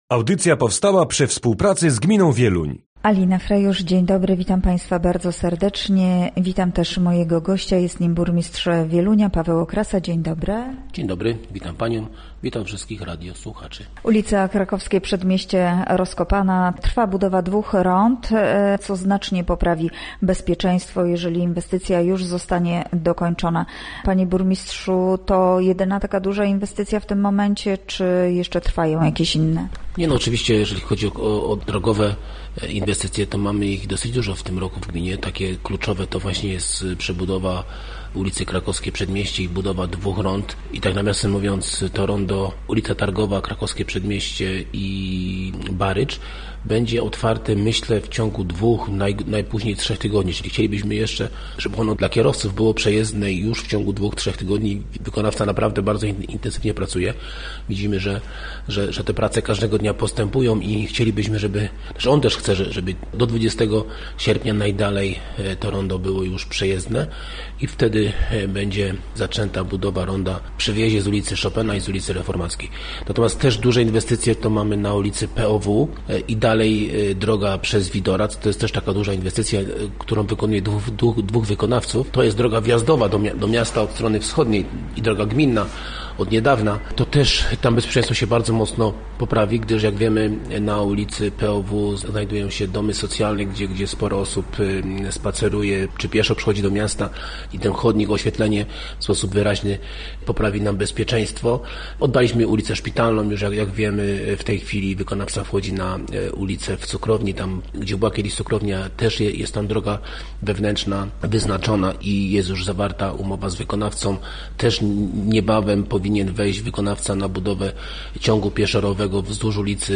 Gościem Radia ZW był Paweł Okrasa, burmistrz Wielunia